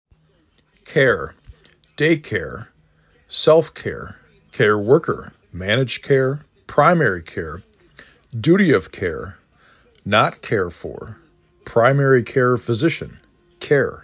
4 Letters, 1 Syllable
3 Phonemes
k e r